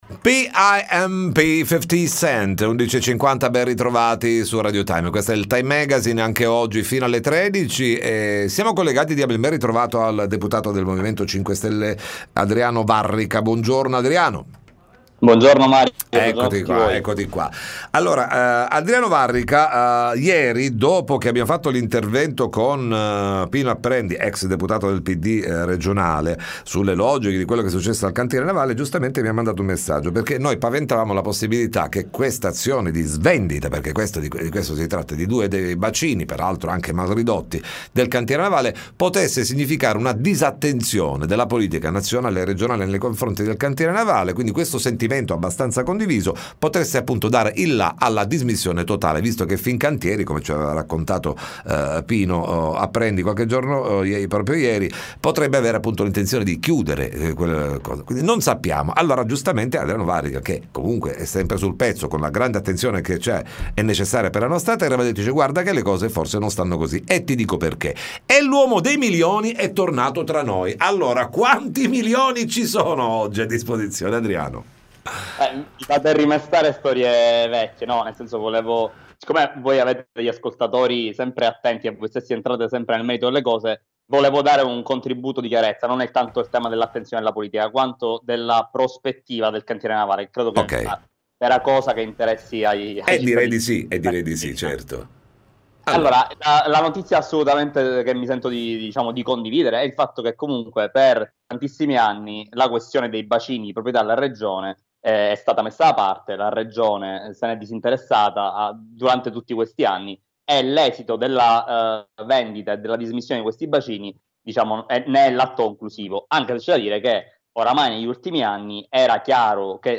TM intervista On. A. Varrica